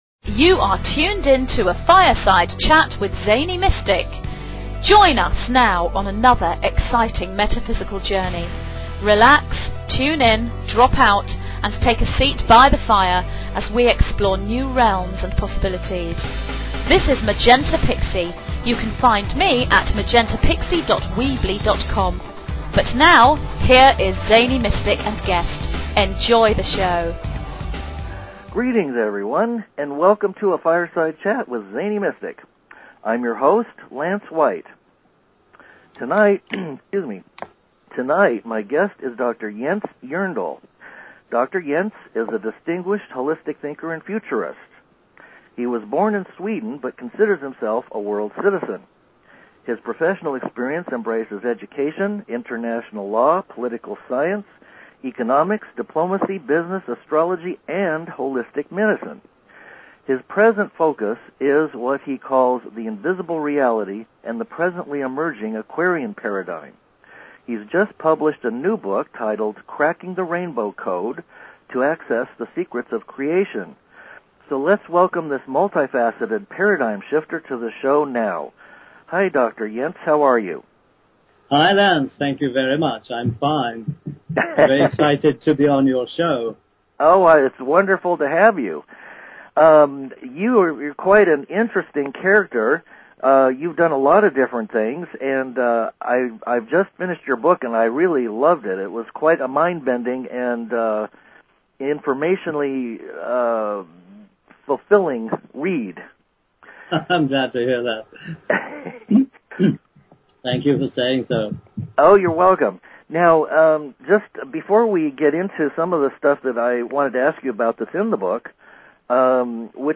Talk Show Episode
Please note that during this show, there were numerous technical problems due to the communications devices "dropping" suddenly. We all did the best we could, but do be prepared for several "gliches".